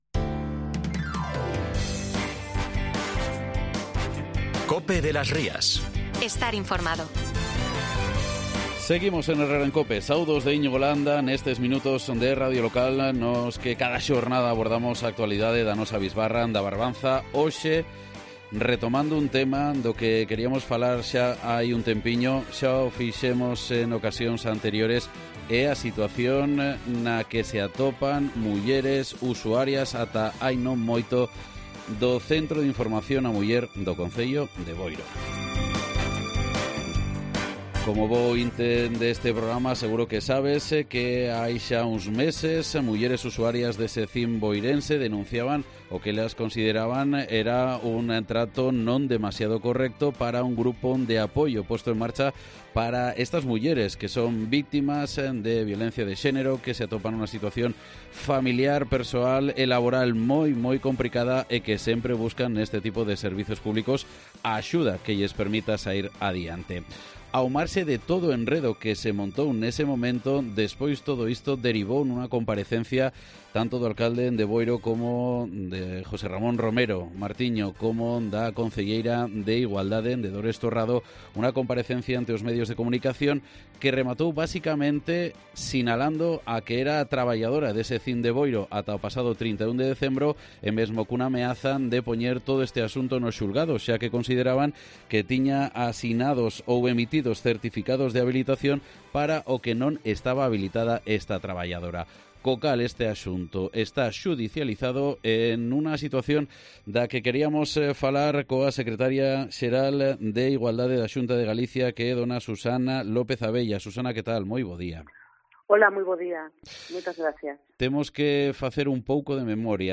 AUDIO: Hablamos con la Secretaria Xeral de Igualdade de la Xunta de Galicia, Susana López Abella, sobre la polémica suscitada en Boiro por...